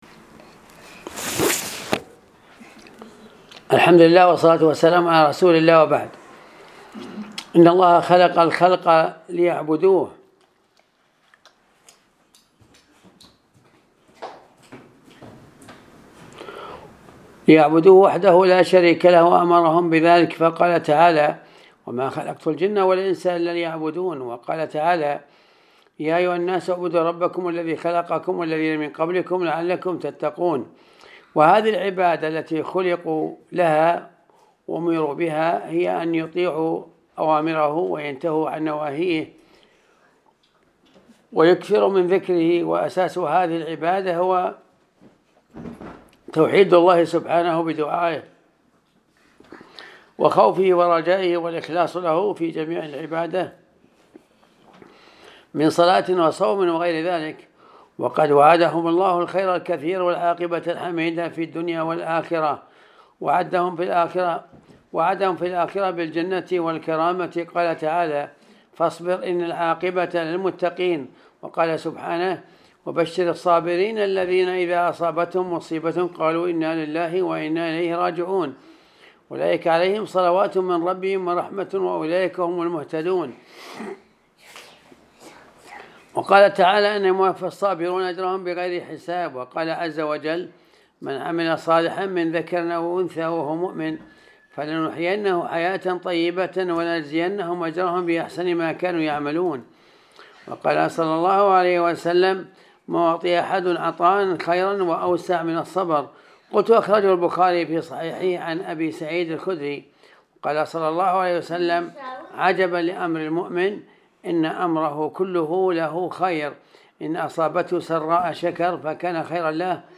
الدرس-270-ج5-أجر-الصابرين-في-الدنيا-والآخرة.mp3